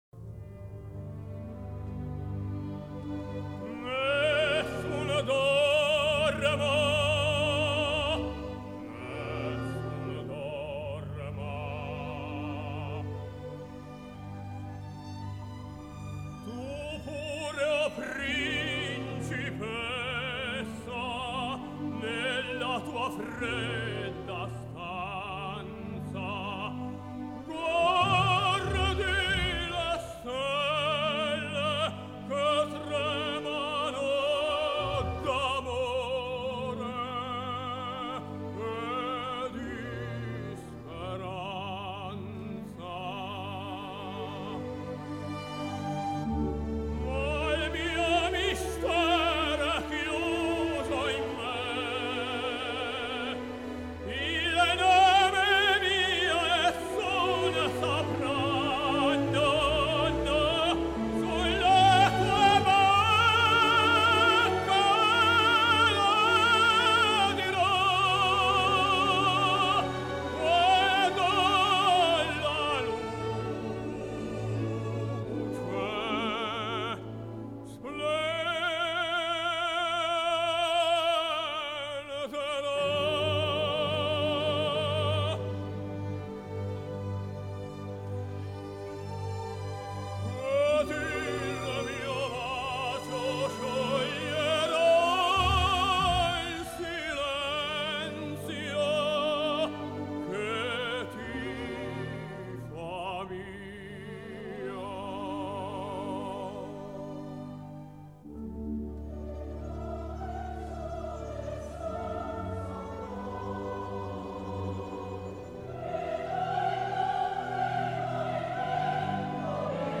一方面我对他有偏爱，另一方面他忧郁的音色和火山爆发般的热情与角色所处的地位和境遇是吻合的。